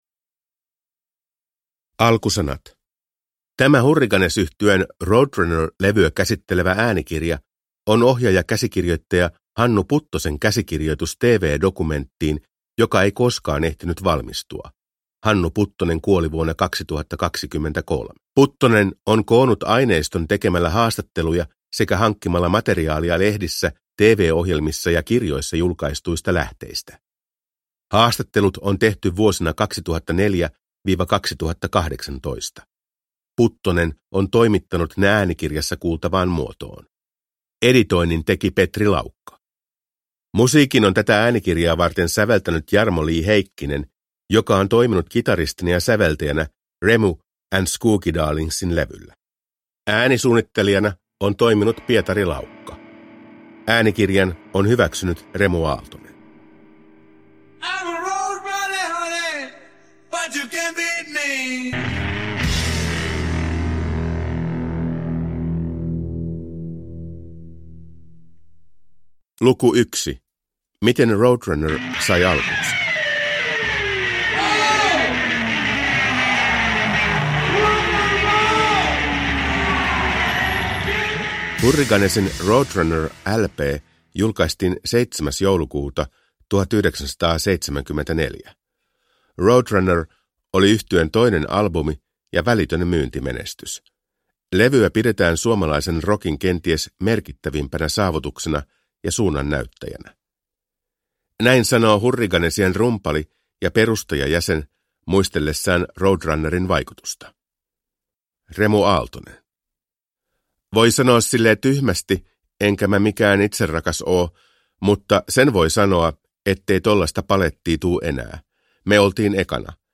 Stop the building – Ljudbok